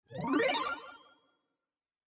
UI_Progressbar.mp3